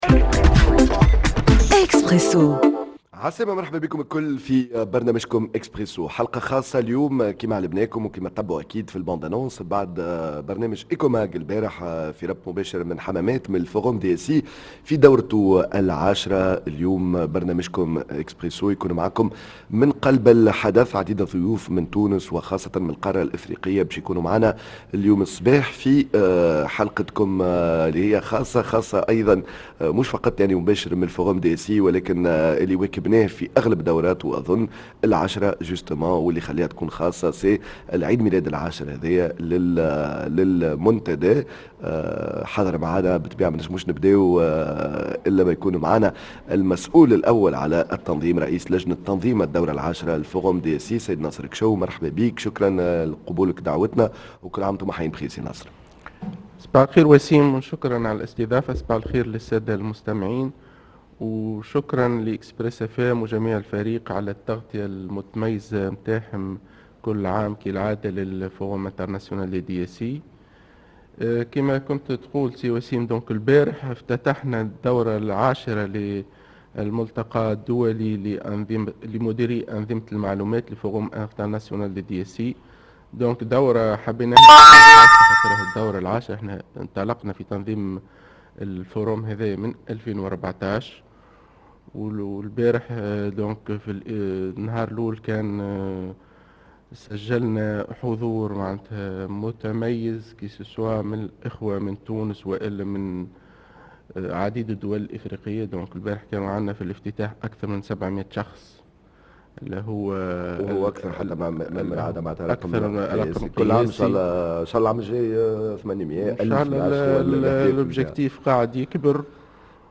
dans un plateau spécial en direct de Yasmine El Hammamet